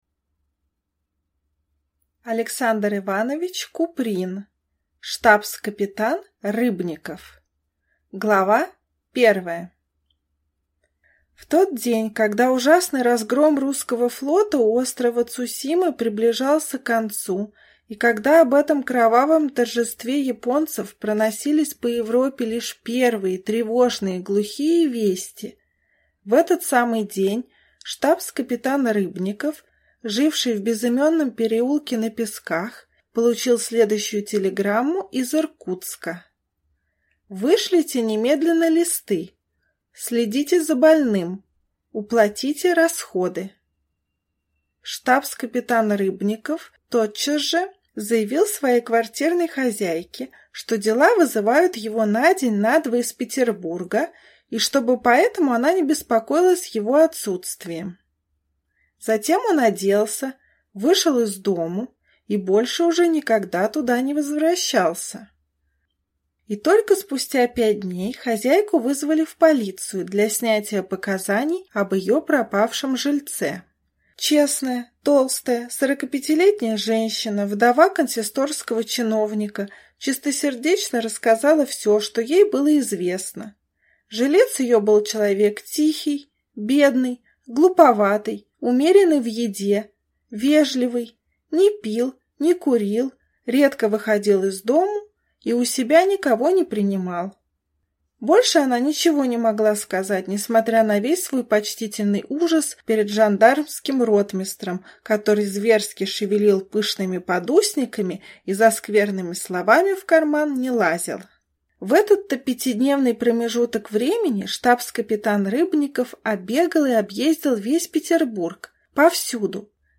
Аудиокнига Штабс-капитан Рыбников | Библиотека аудиокниг